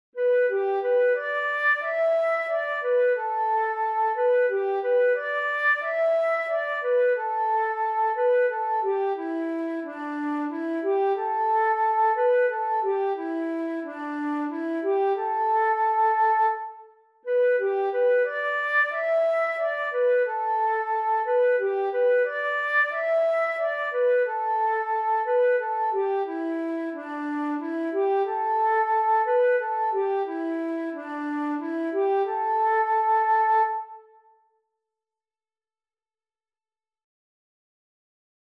Een liedje voor een kringspelletje
dit liedje is pentatonisch